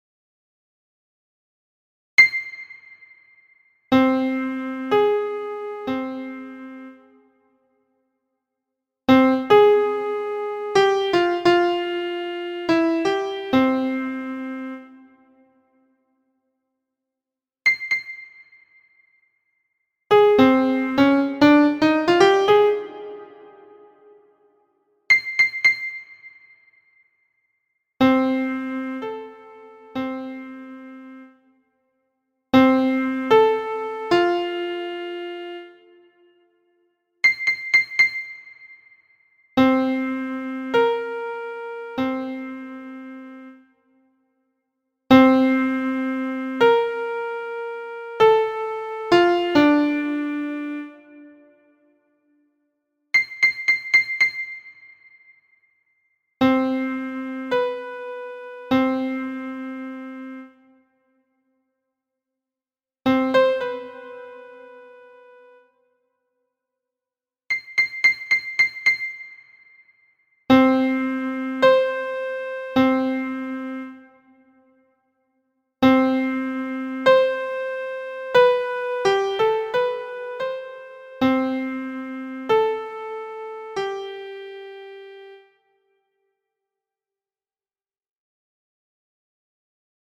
This section contains some MIDI files that demonstrate some interval in the context of some songs.
Sixths, Sevenths and the Octave